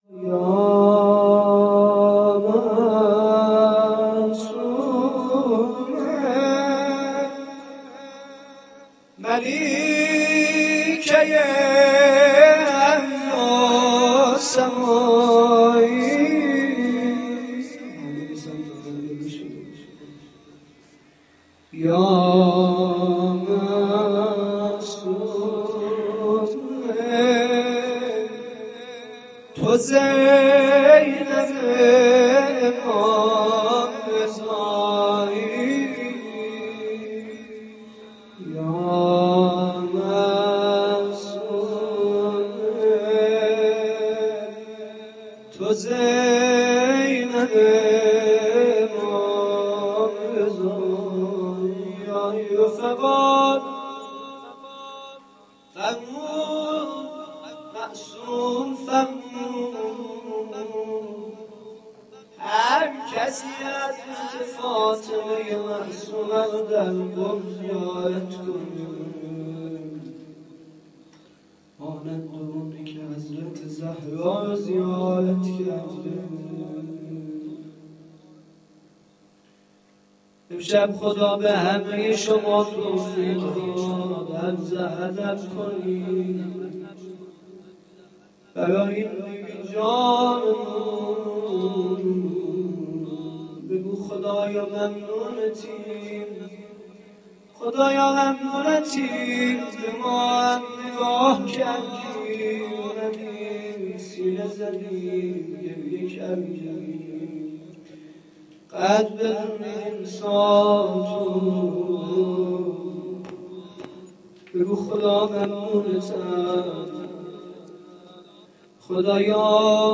زمزمه - یا معصومه ملیکه ارض و سمایی - شب وفات حضرت معصومه ۱۳۹۷ - محفل فدائیان زینب کبری بشرویه